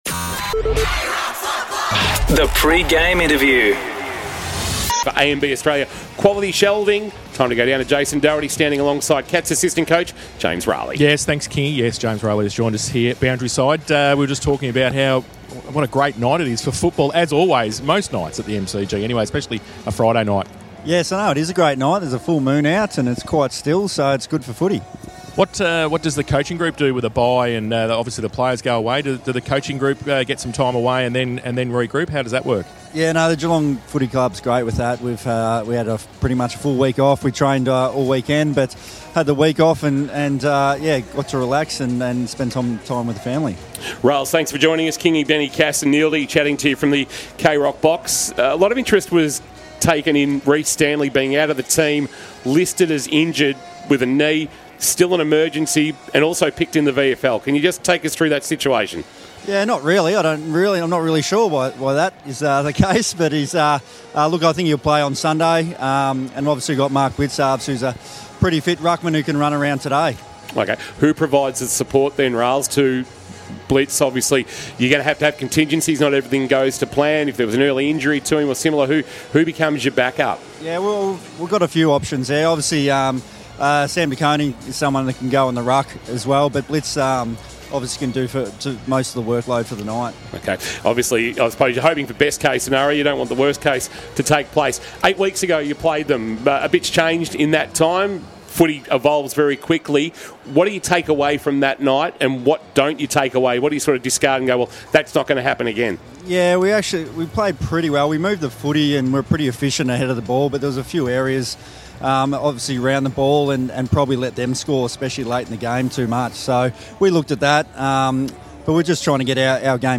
2024 - AFL - Round 15 - Carlton vs. Geelong: Pre-match interview